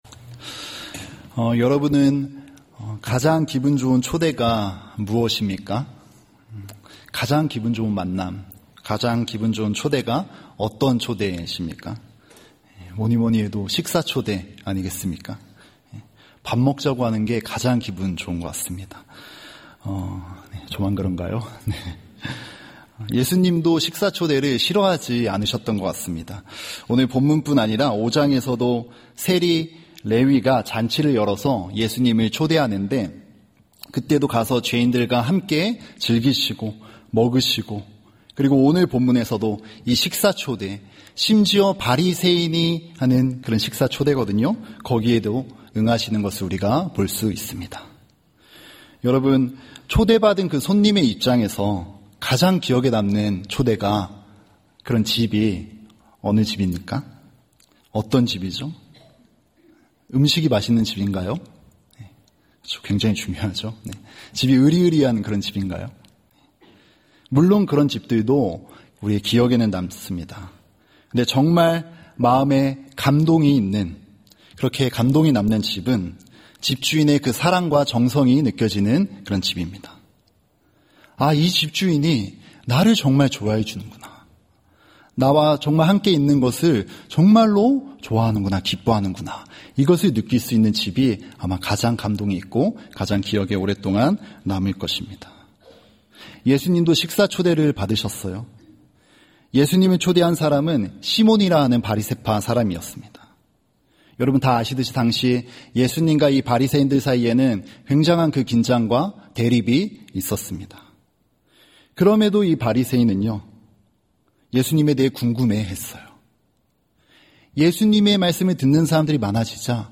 예배 수요예배